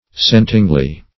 scentingly - definition of scentingly - synonyms, pronunciation, spelling from Free Dictionary Search Result for " scentingly" : The Collaborative International Dictionary of English v.0.48: Scentingly \Scent"ing*ly\, adv.